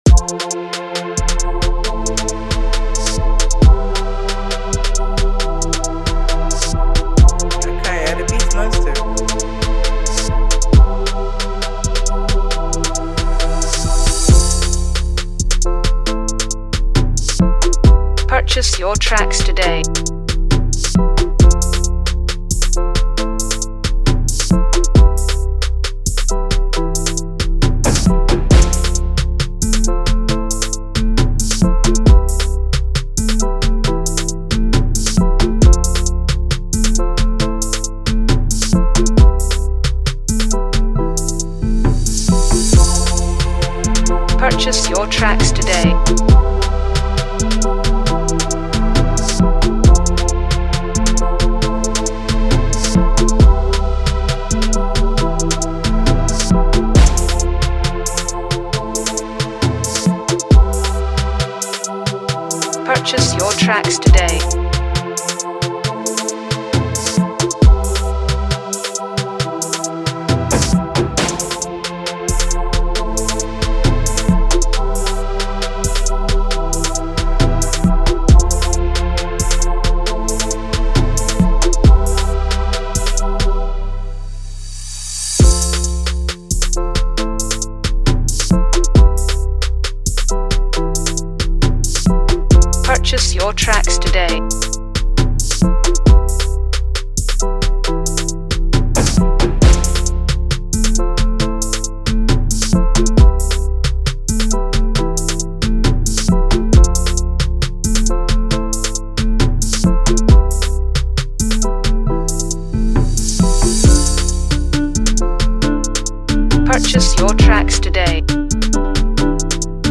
vibrant Afrobeat instrumental
delivers high-energy vibes